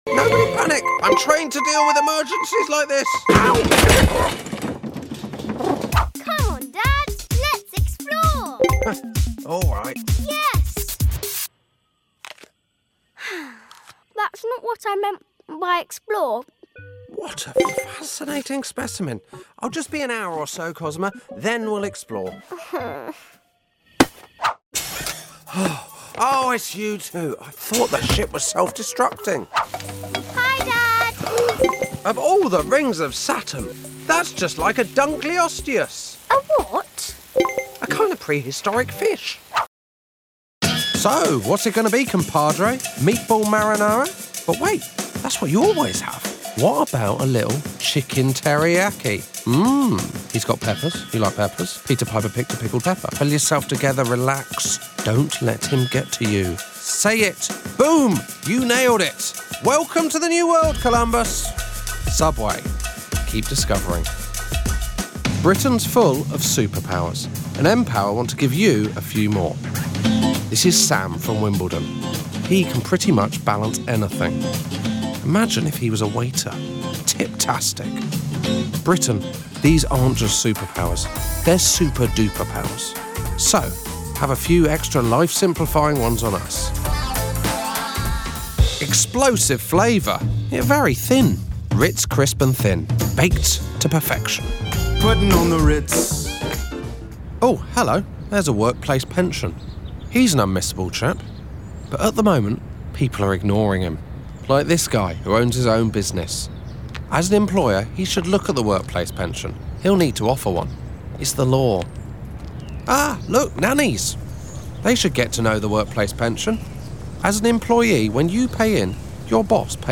VOICEOVERS
Voiceover-Josh-Widdicombe.m4a